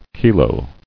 [ki·lo]